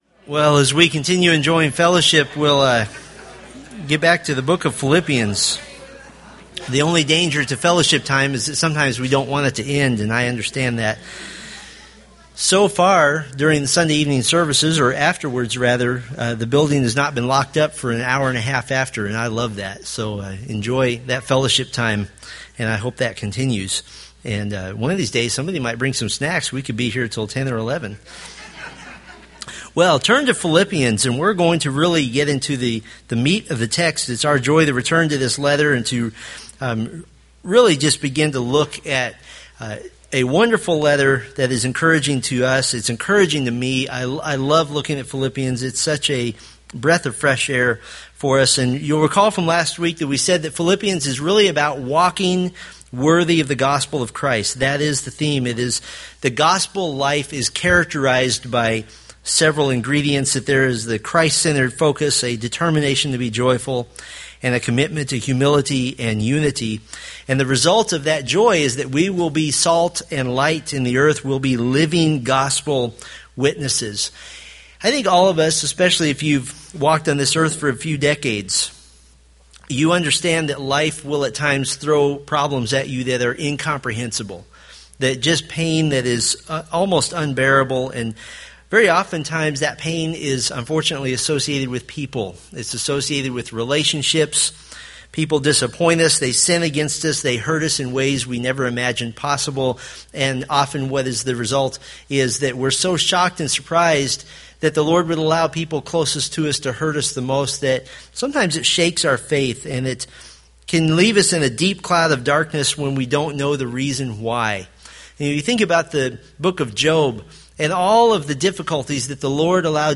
Philippians Sermon Series